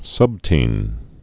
(sŭbtēn)